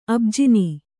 ♪ abjini